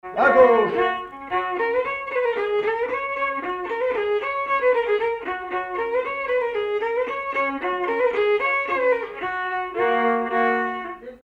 Mazurka partie 2
Ugine
danse : mazurka
circonstance : bal, dancerie
Pièce musicale inédite